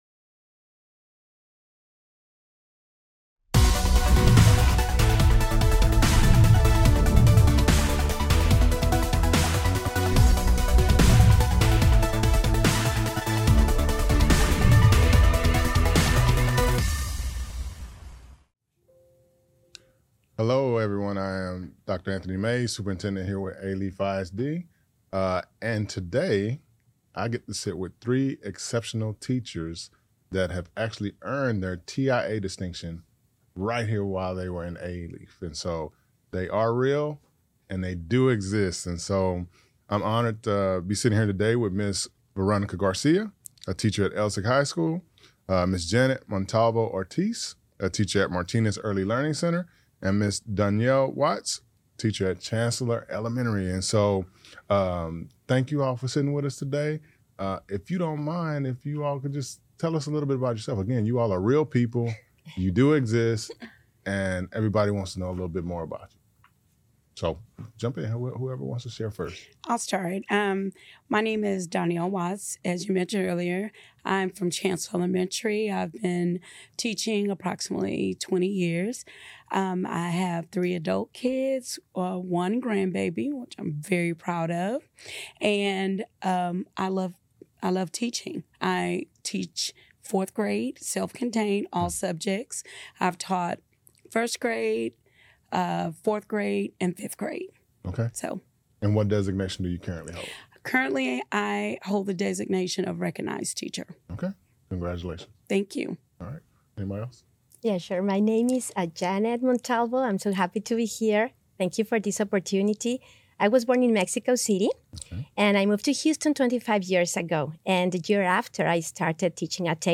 In this episode of the Alief ISD Podcast, Superintendent Dr. Anthony Mays sits down with three exceptional educators who have earned the prestigious Teacher Incentive Allotment (TIA) distinction in Alief ISD.